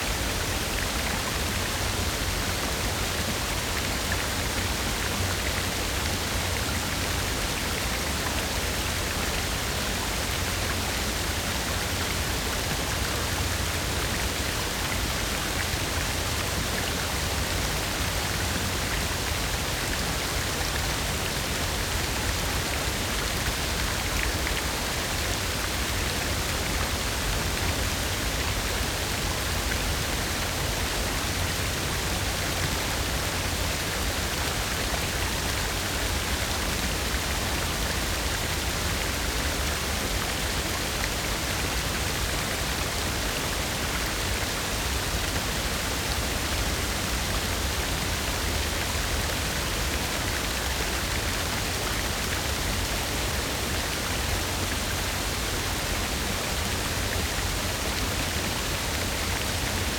Waterfalls Rivers and Streams
Waterfall Loop.ogg